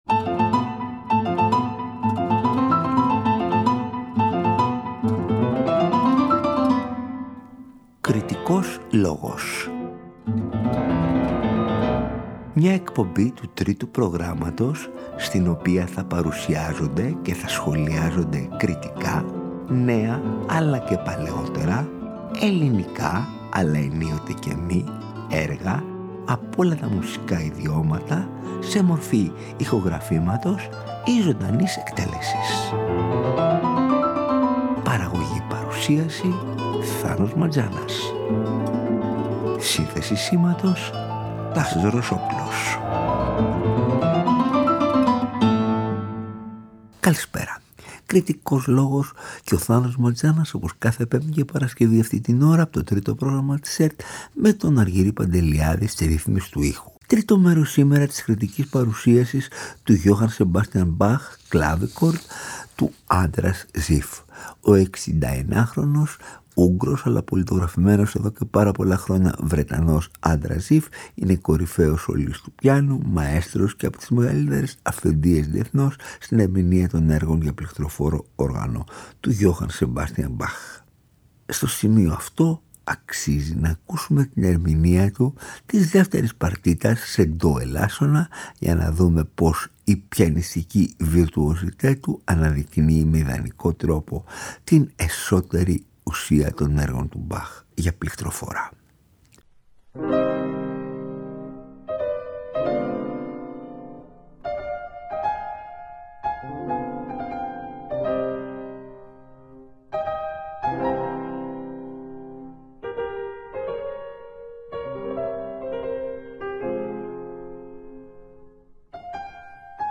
με έργα για πληκτροφόρο όργανο
κλαβίχορδο